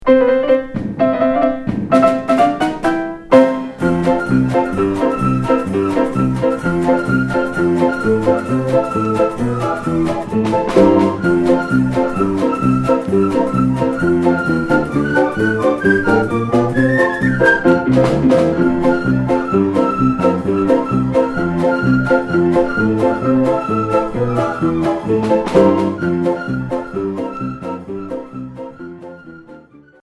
Orchestre enfantin